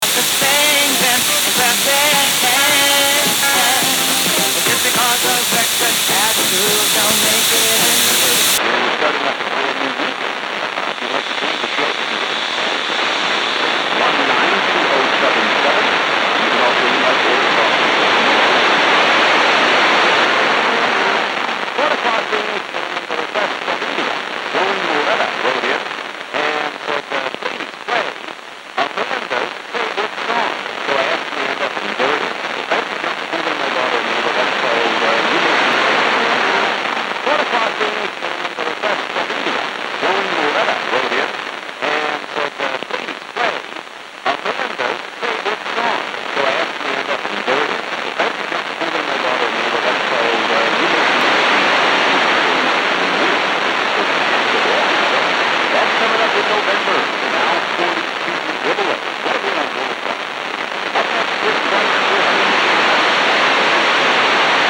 This was a radio station, with a music format.
It was difficult to determine the accent. One moment it sounded American, but then it may have been something else. I dont think it was a harmonic, as it seemed clear on FM mode, using WFM, when there was a rare signal peak.